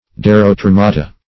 Search Result for " derotremata" : The Collaborative International Dictionary of English v.0.48: Derotremata \Der`o*tre"ma*ta\, n. pl.